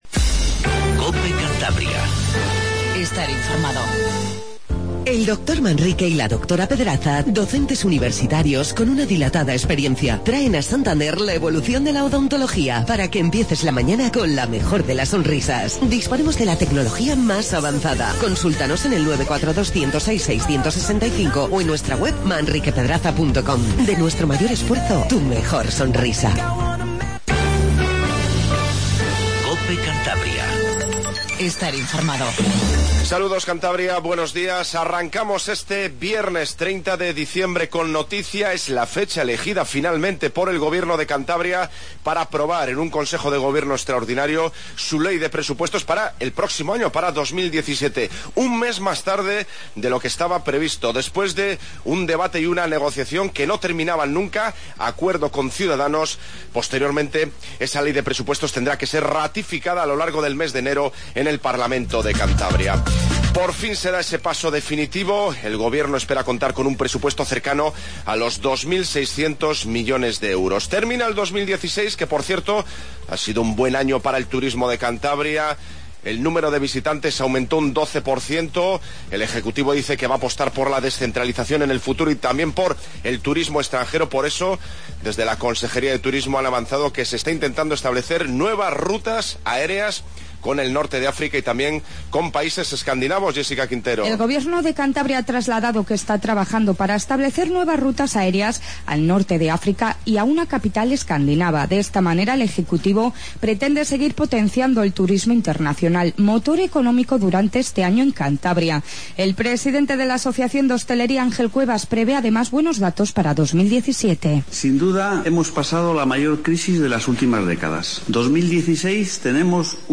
INFORMATIVO MATINAL 07:20